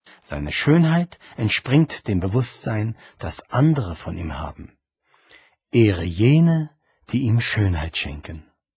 Hörbuch, 68 Minuten
Gelesen von Christian Anders